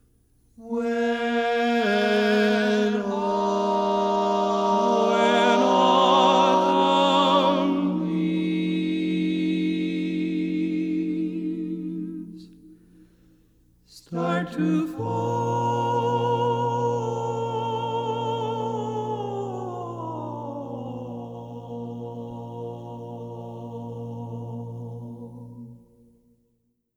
Key written in: B♭ Minor
How many parts: 4
Type: Barbershop
All Parts mix: